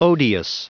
Prononciation du mot odious en anglais (fichier audio)
Prononciation du mot : odious